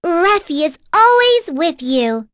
Rephy-Vox_Victory.wav